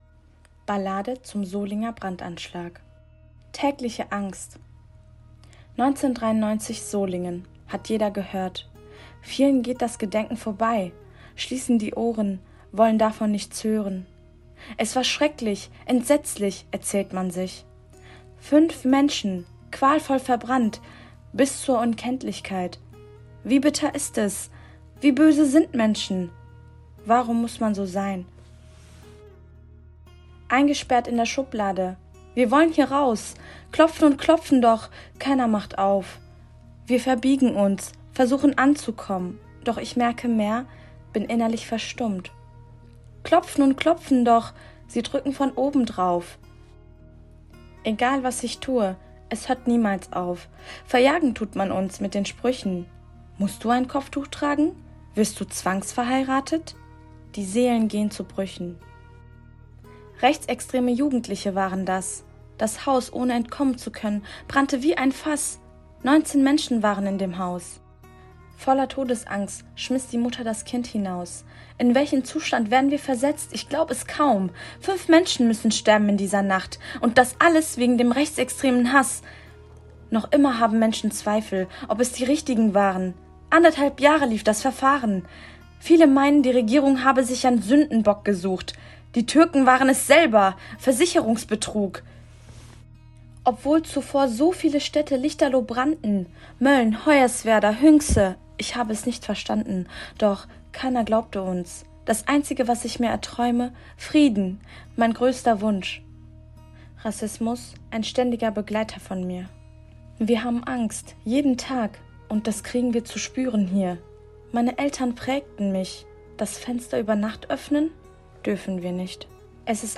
Ballade „Tägliche Angst
Ballade_zum_Solinger_Brandanschlag.mp3